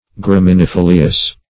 Search Result for " graminifolious" : The Collaborative International Dictionary of English v.0.48: Graminifolious \Gram"i*ni*fo"li*ous\, a. [L. gramen, graminis, grass + folium leaf.]